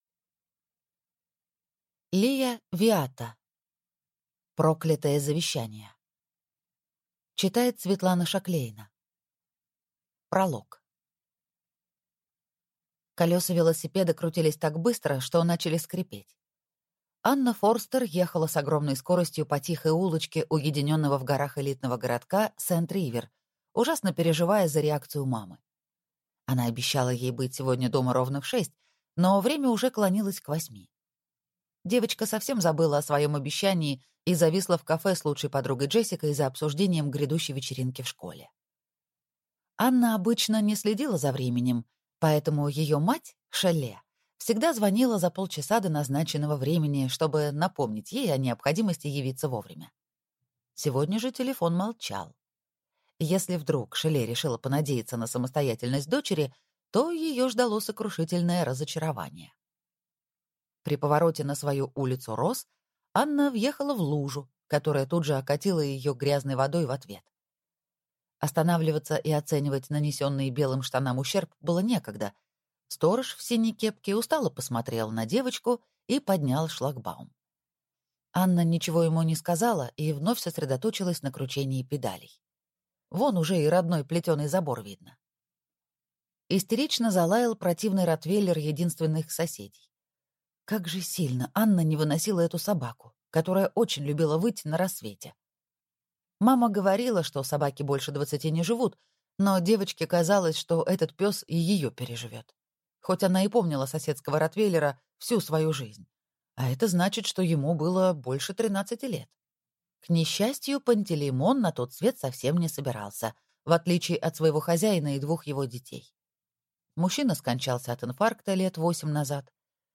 Аудиокнига Проклятое завещание | Библиотека аудиокниг